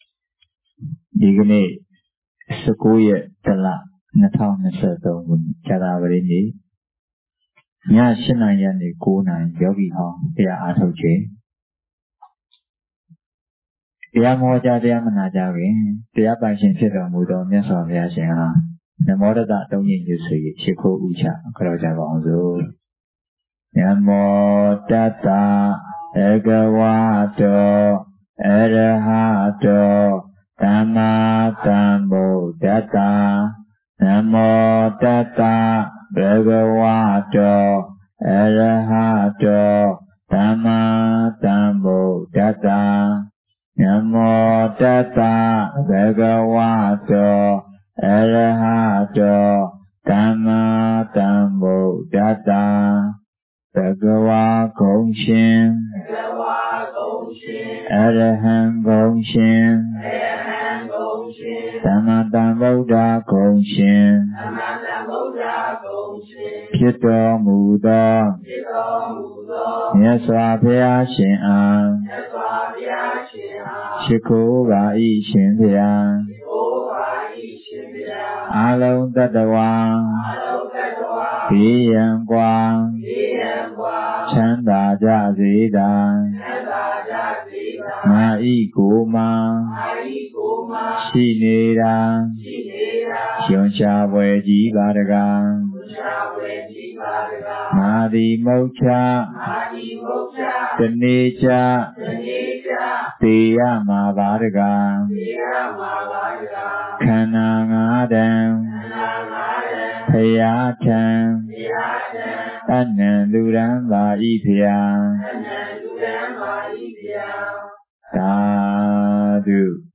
Jan19 2023 ညစဉ်တရားပွဲ